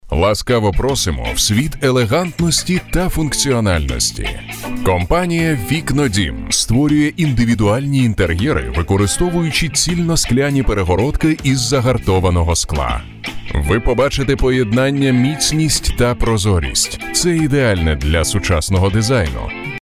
Мужские дикторы, мужские голоса для озвучки — Студия звукозаписи Seventh Heaven
Демо голоса: